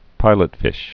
(pīlət-fĭsh)